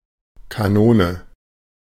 Ääntäminen
Vaihtoehtoiset kirjoitusmuodot (painaminen) canon Synonyymit carom Ääntäminen US Tuntematon aksentti: IPA : /ˈkæn.ən/ Haettu sana löytyi näillä lähdekielillä: englanti Käännös Konteksti Ääninäyte Substantiivit 1.